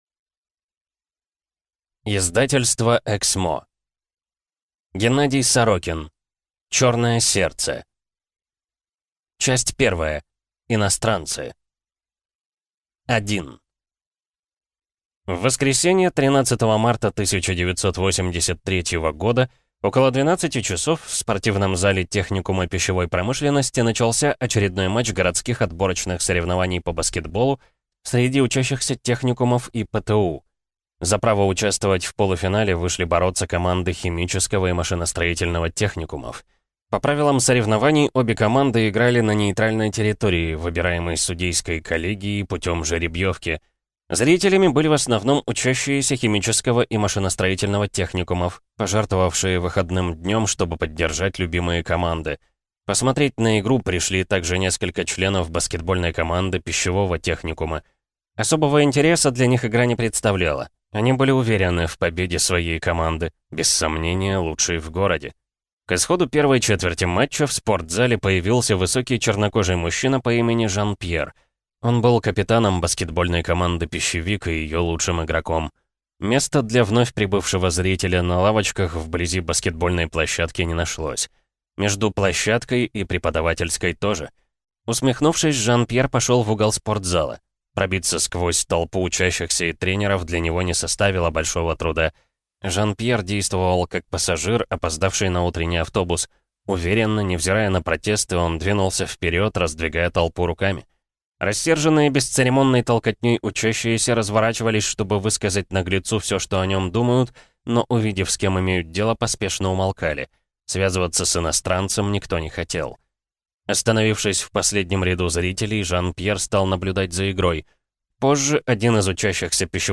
Аудиокнига Черное сердце | Библиотека аудиокниг